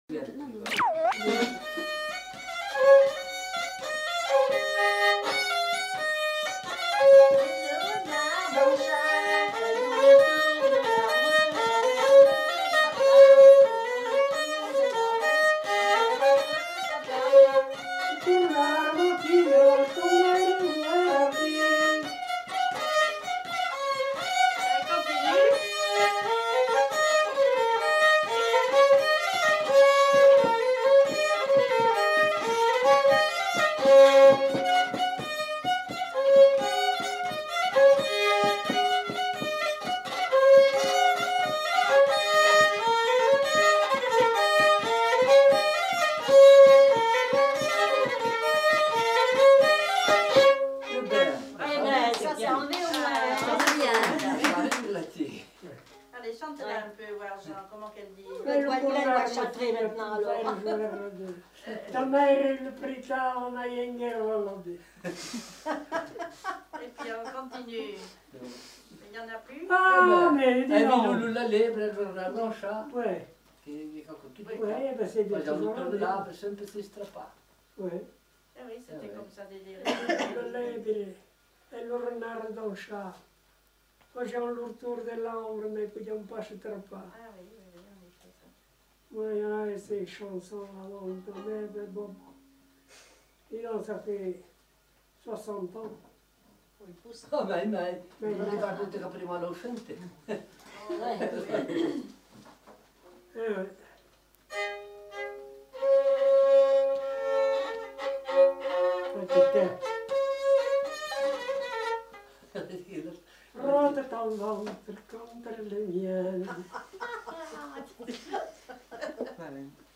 Aire culturelle : Limousin
Lieu : Lacombe (lieu-dit)
Genre : morceau instrumental
Instrument de musique : violon
Danse : bourrée
Notes consultables : Le second violon est joué par un des enquêteurs.